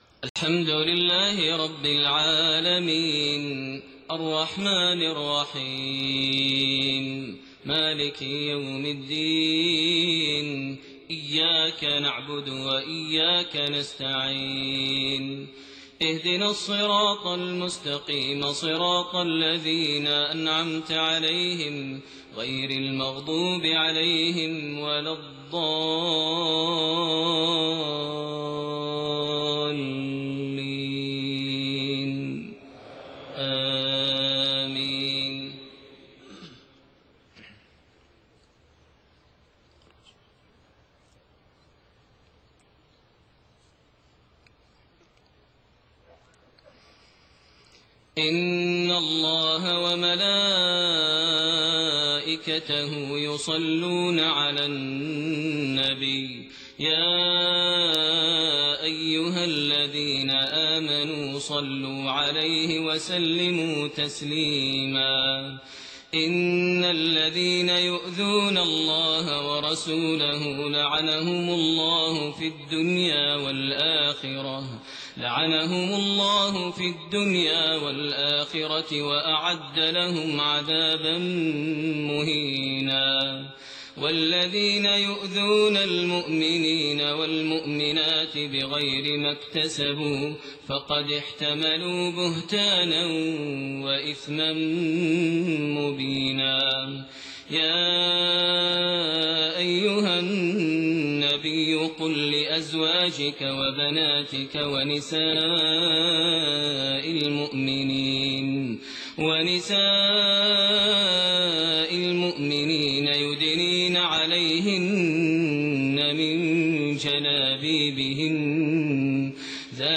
Fajr prayer from Surah Al-Ahzaab > 1429 H > Prayers - Maher Almuaiqly Recitations